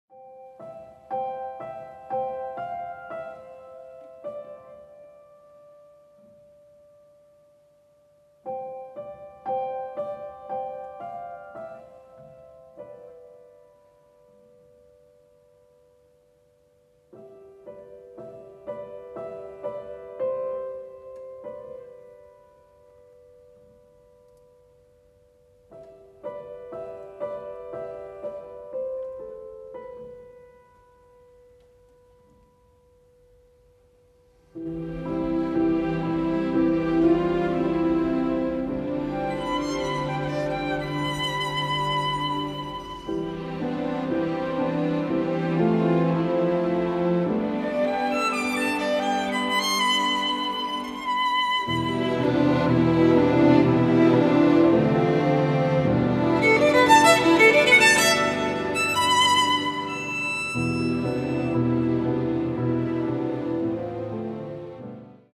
Классическая
для скрипки і камерного оркестру